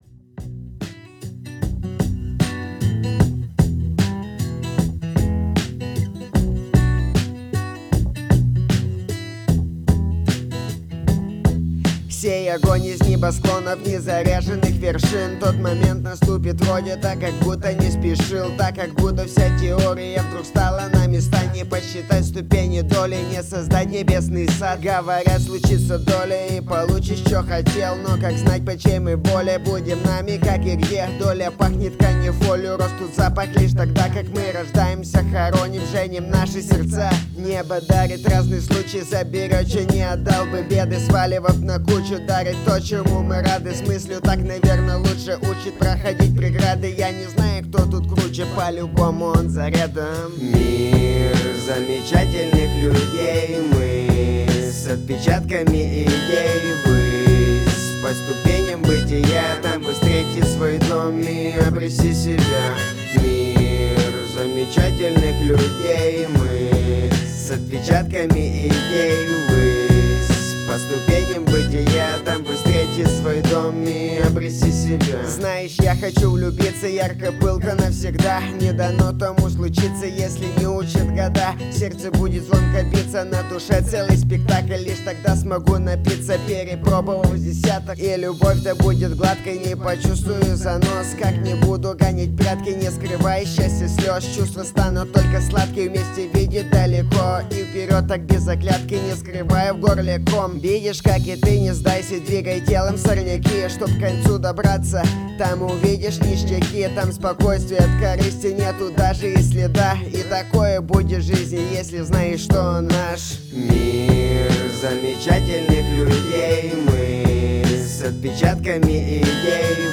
Читаю реп
решил сделать дома миди студию
микро не катит под мой голос